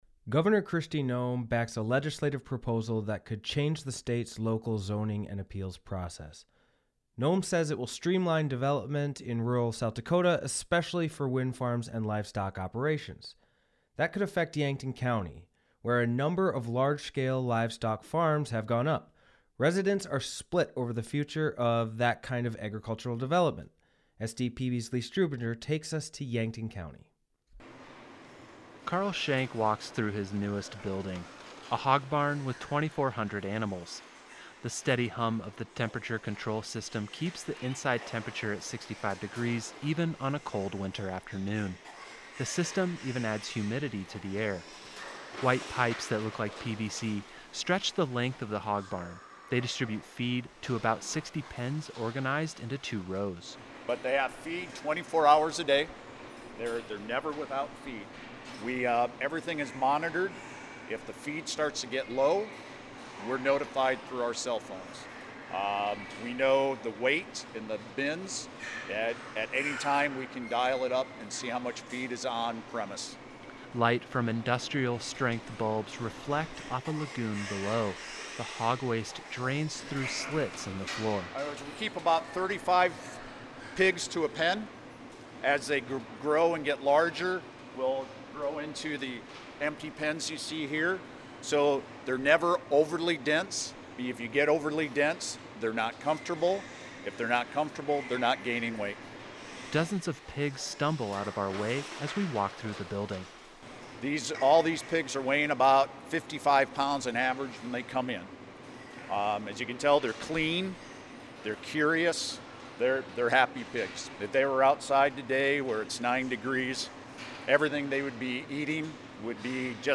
The steady hum of the temperature control systems keeps the inside temperature at 65 degrees even on a cold winter afternoon.
Dozens of pigs stumble out of our way as we walk through the building.